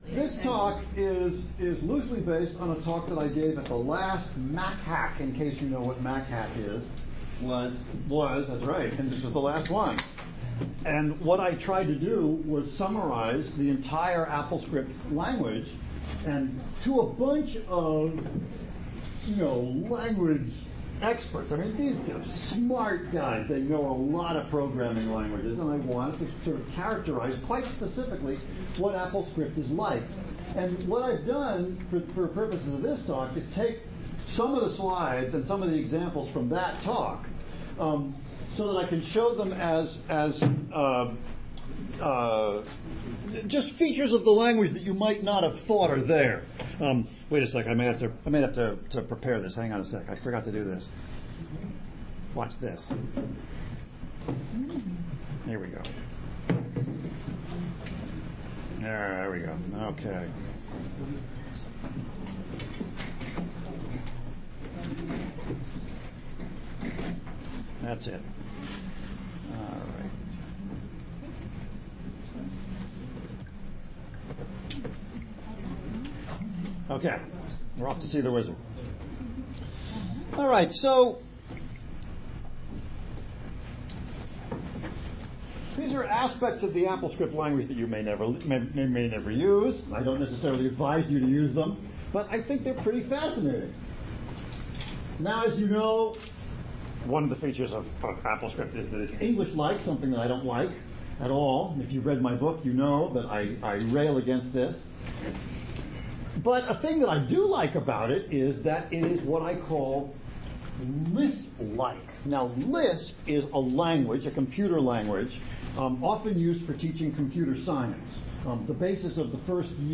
Classes and Lectures